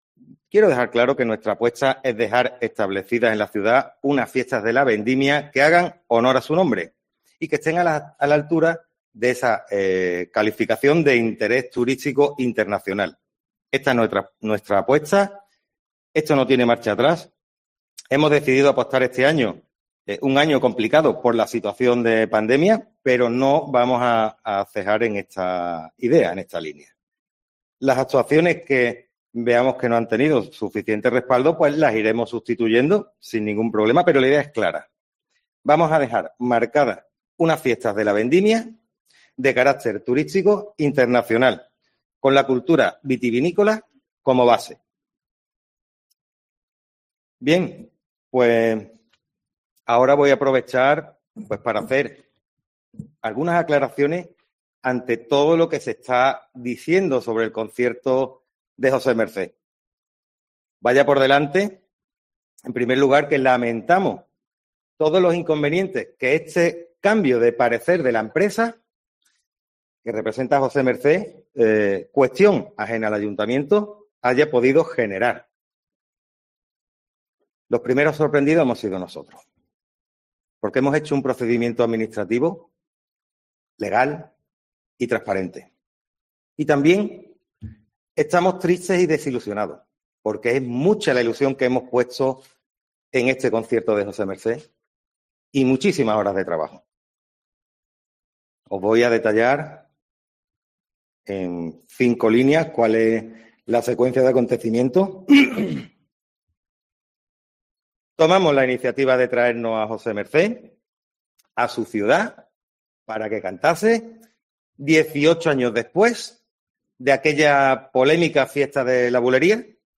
Sus quejas porque se pretenda que su empresa venda las entradas así como denunciando que no se respetara su caché han sido respondidas este lunes en rueda de prensa por el delegado de Fiestas.